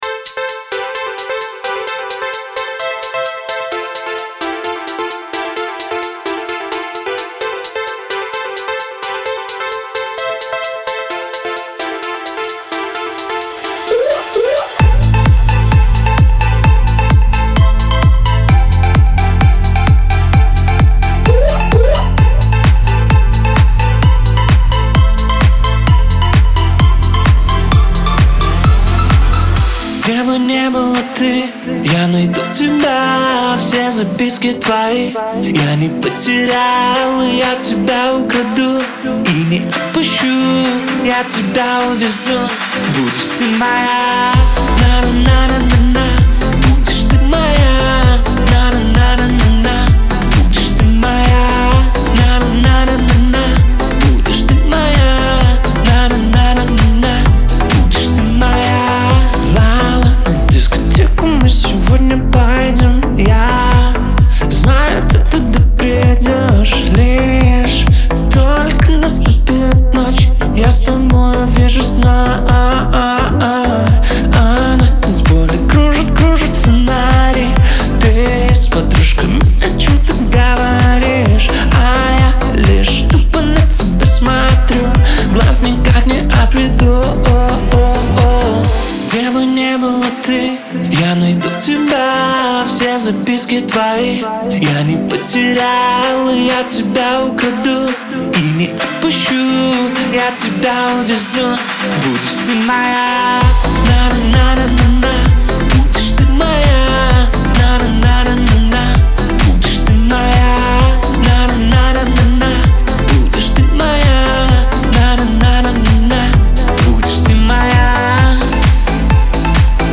мелодичный бит